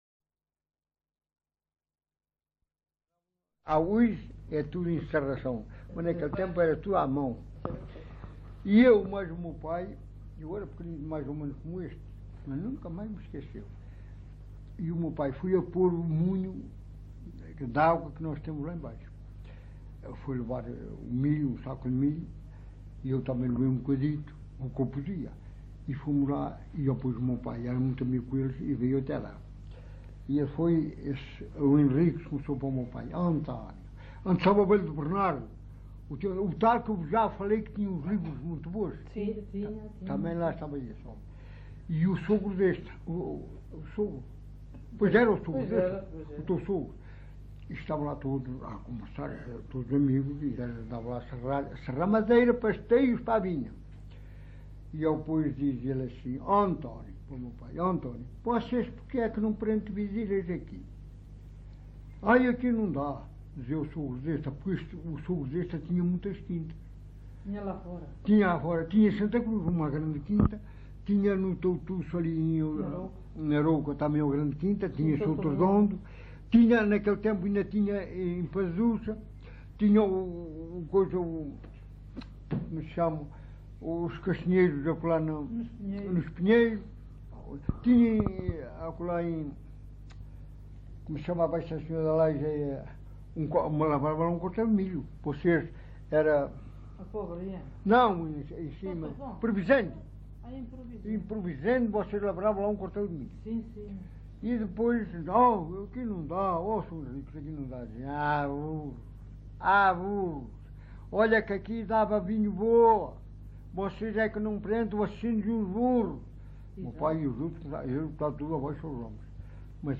LocalidadeCovo (Vale de Cambra, Aveiro)